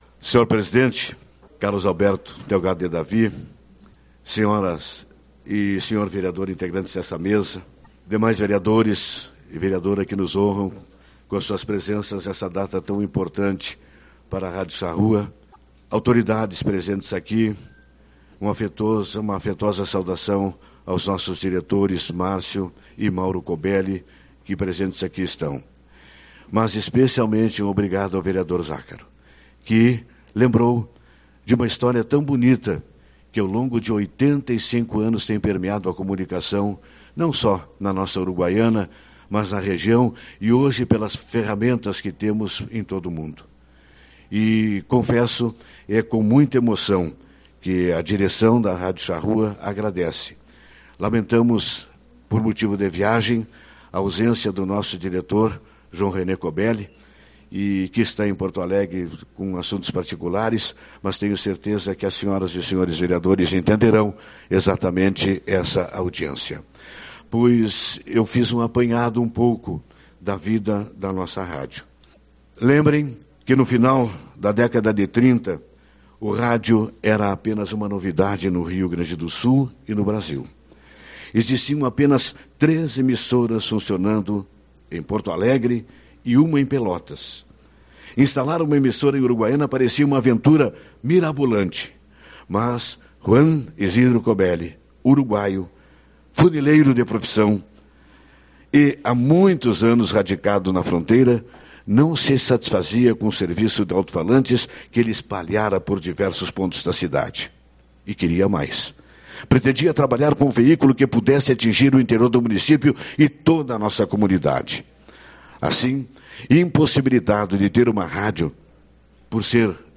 21/10 - Reunião Ordinária